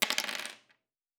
Dice Multiple 1.wav